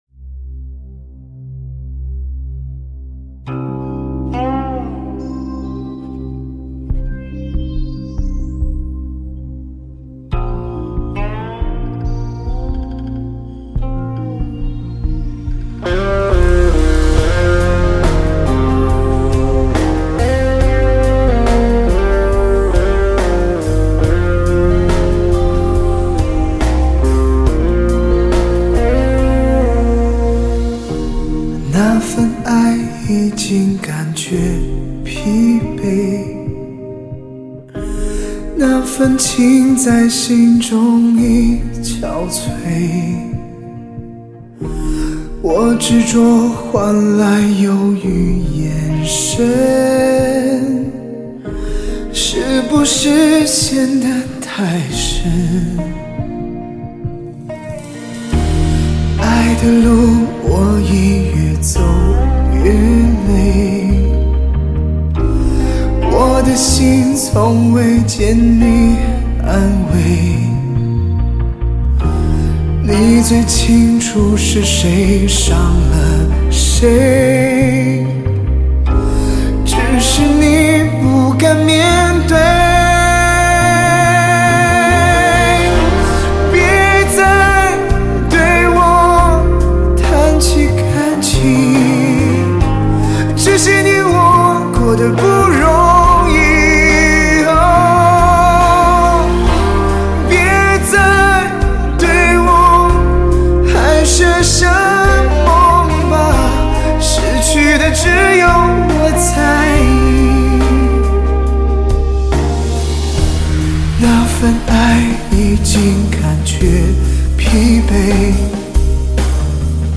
为低音质MP3